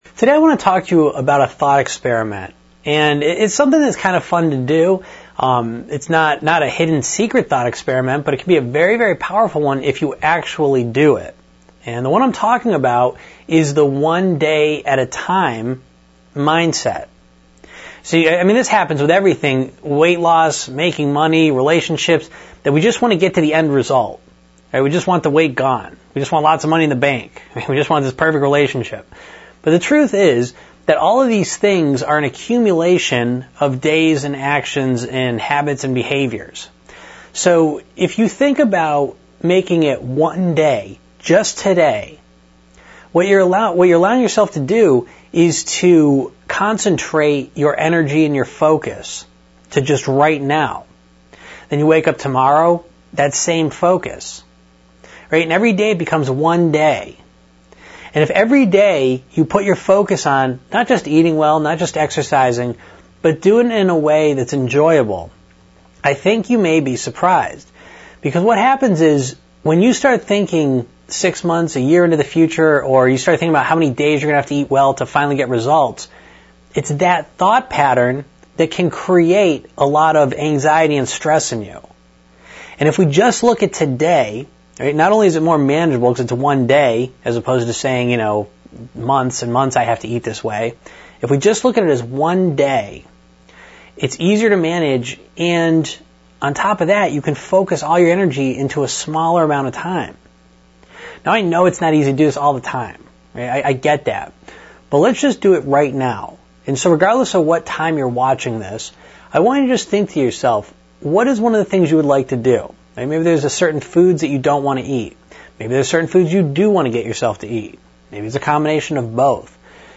Lesson #74